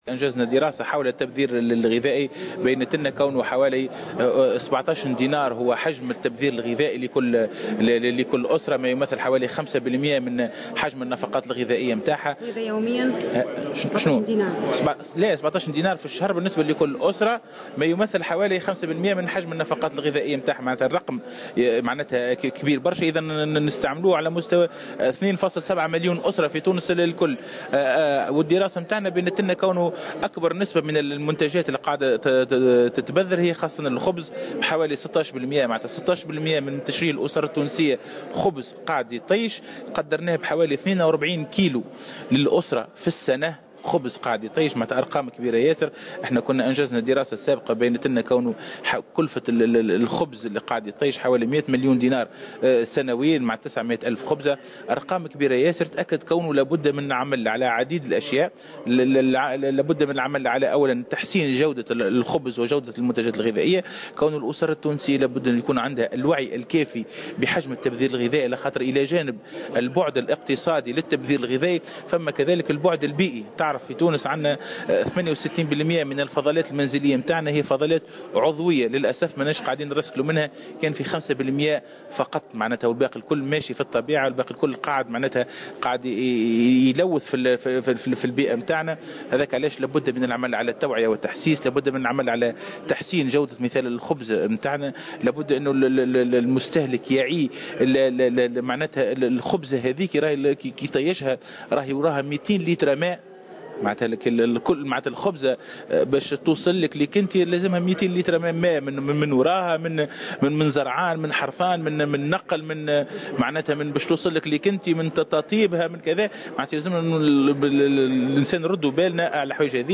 وأضاف بن جازية في تصريح لمراسلة "الجوهرة أف أم" على هامش ورشة عمل "حول التبذير" بالحمامات أن آخر دراسة قام بها المعهد خلصت إلى أن كلفة تبذير الخبر في تونس بلغت حوالي 100 مليار سنويا ( 42 كلغ من الخبز تتلفه كل أسرة سنويا).كما أوضح أيضا أن كلفة التبذير الغذائي في تونس تقدّر بـ 17 د شهريا لكل أسرة.